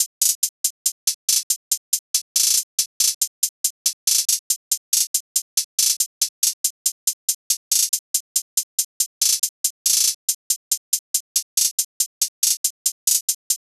SOUTHSIDE_beat_loop_herb_hihat_140.wav